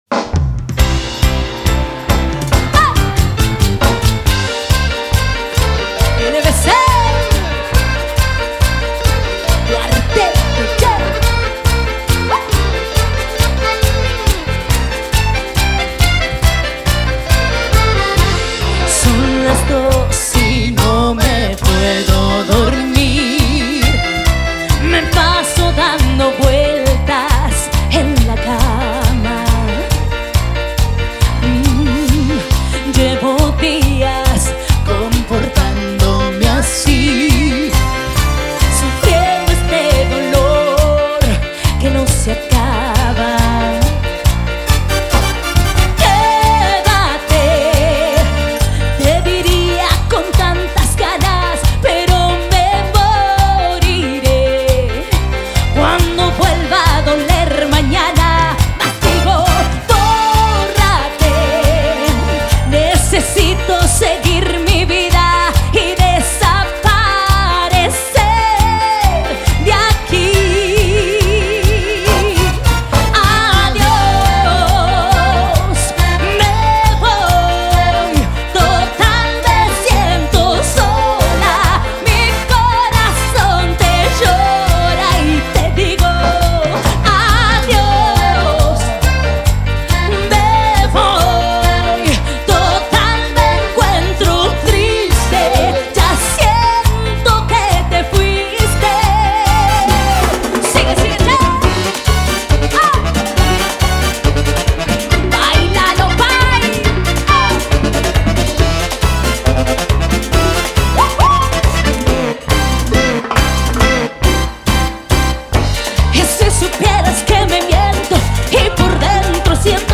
Carpeta: Cumbia y + mp3
En Vivo